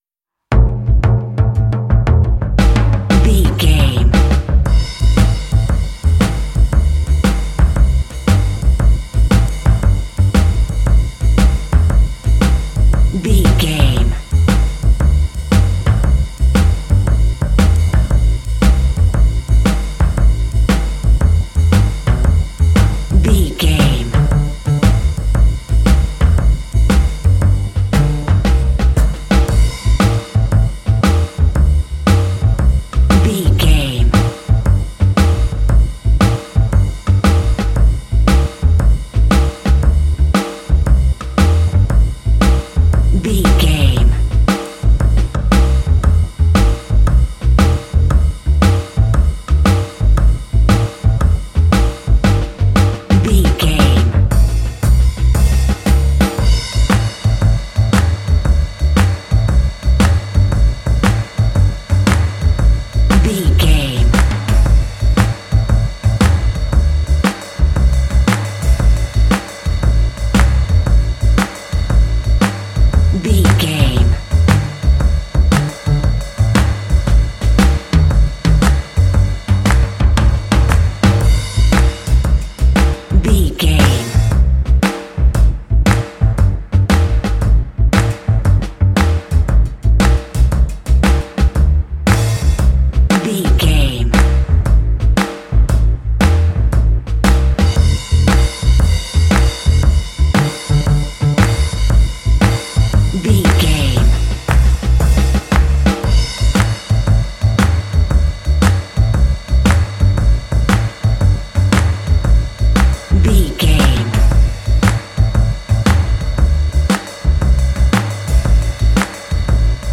This 12-bar blues track
Uplifting
Aeolian/Minor
groovy
melancholy
bouncy
drums
double bass
blues